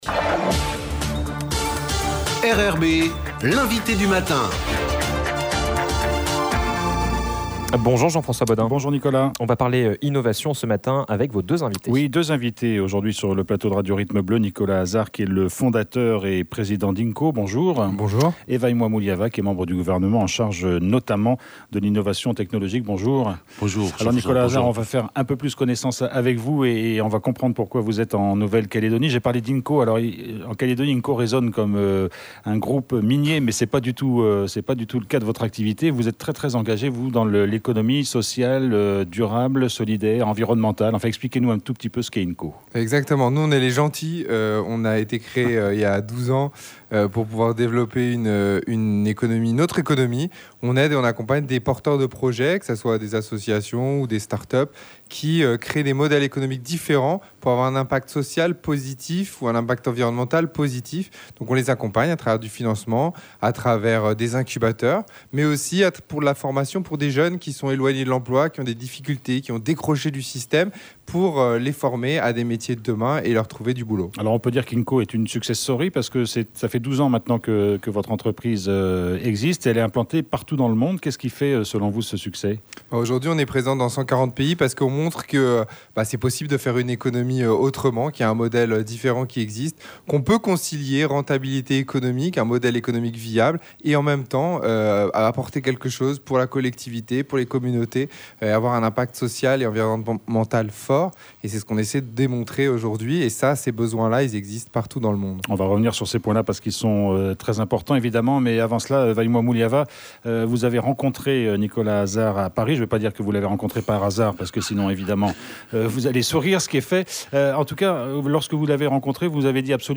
Vaimu'a Muliava membre du gouvernement en charge de l’innovation technologique